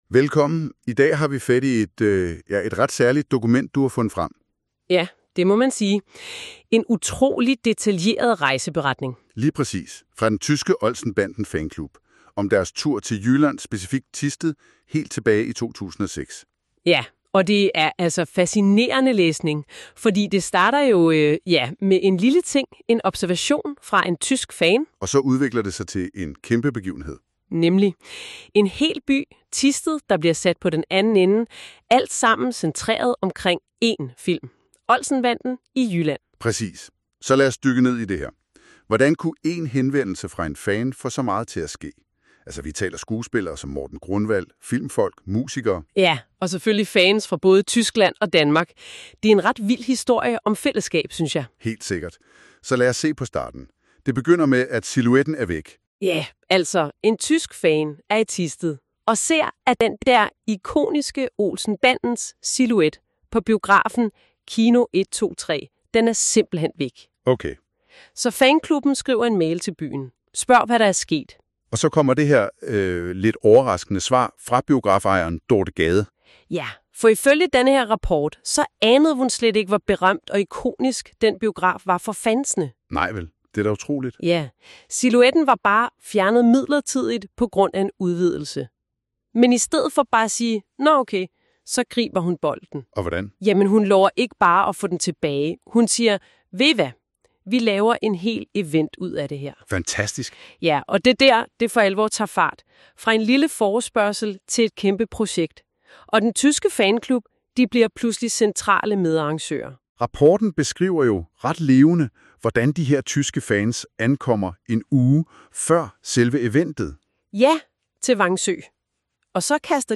Lydresumé i podcastformat
MP3 (KI-genereret lydindhold)